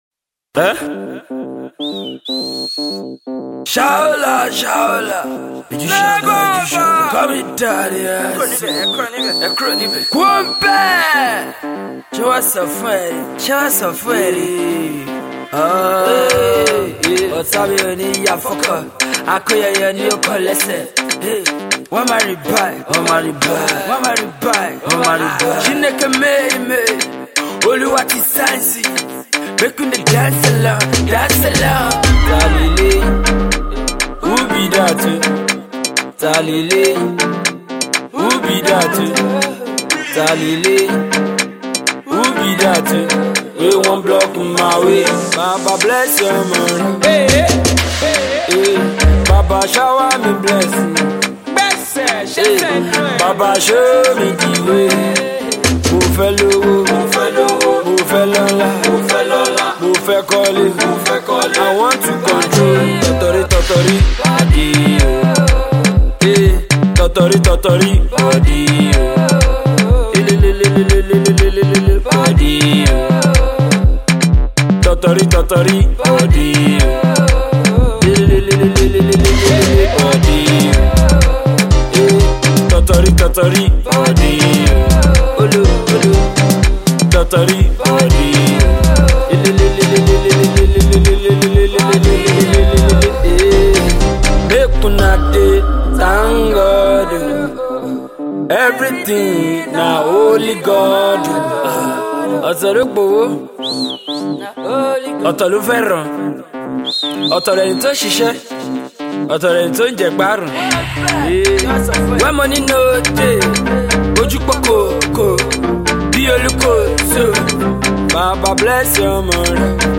street pop